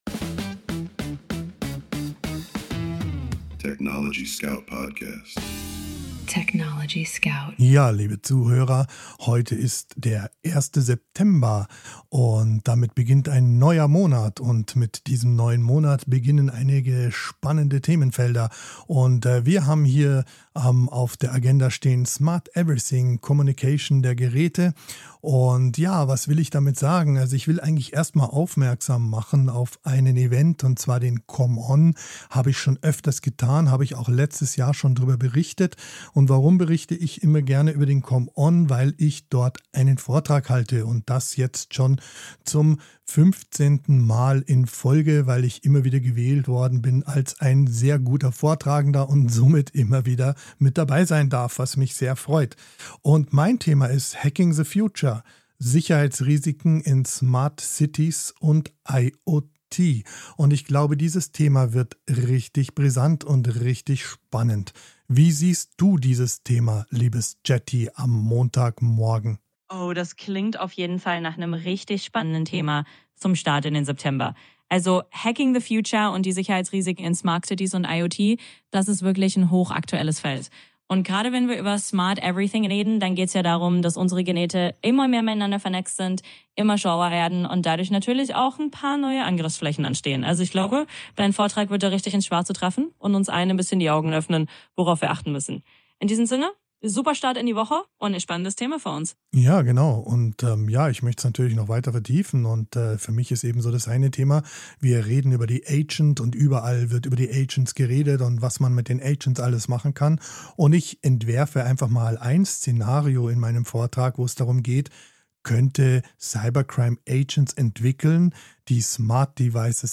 Im Studio des TechnologieScouts